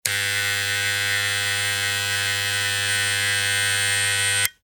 "Sixtant" SM 31 electric shaver
0081_Ein-_und_Ausschaltknopf.mp3